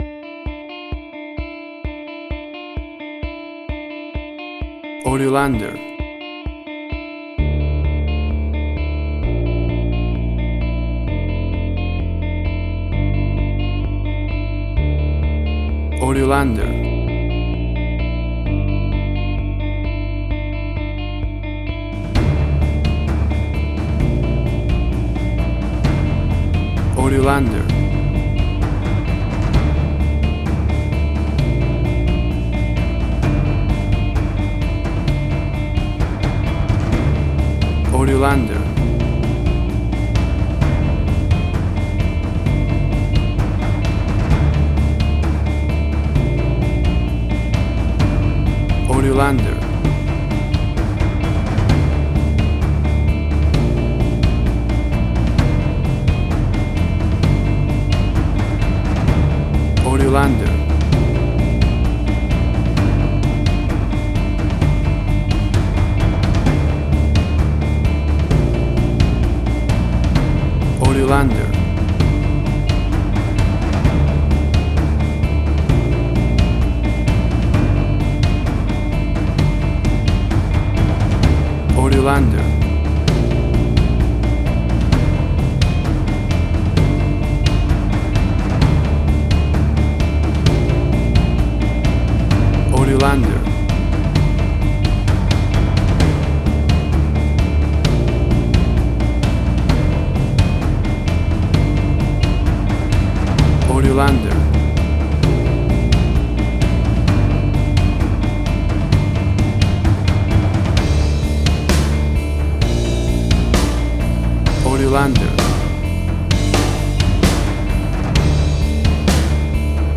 Suspense, Drama, Quirky, Emotional.
Tempo (BPM): 130